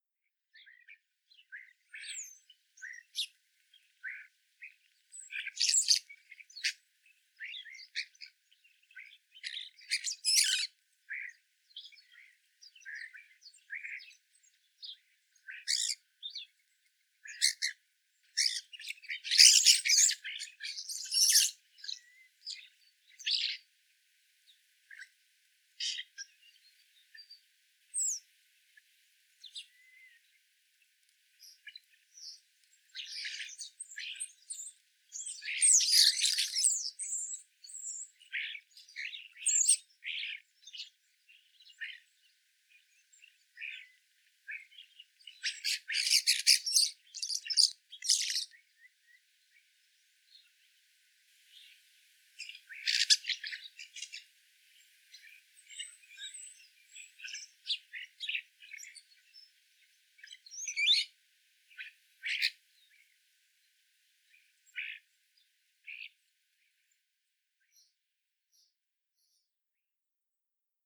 На этой странице собраны разнообразные звуки скворцов: от мелодичного пения до характерного свиста.
Звук скворца в саду